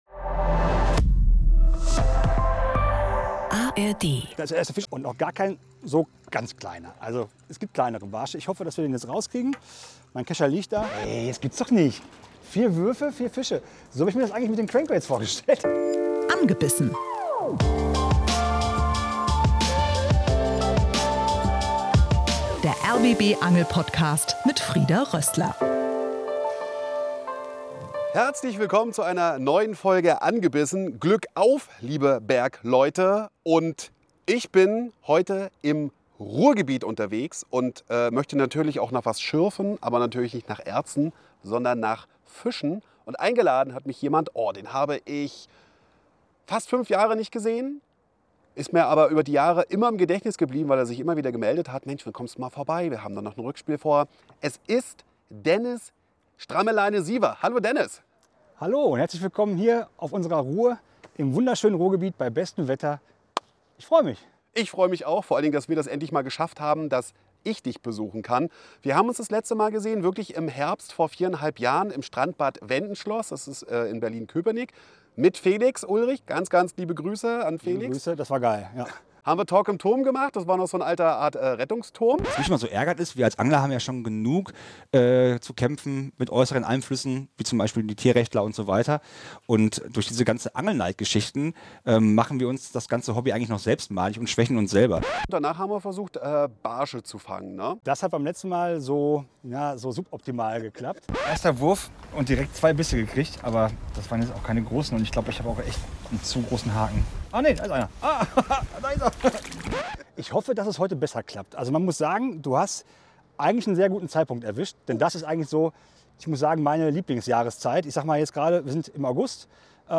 Gemeinsam sind die beiden im Ruderboot unterwegs, in Essen auf der Ruhr. Zielfisch: Der Barsch. Nebenbei wird getalkt.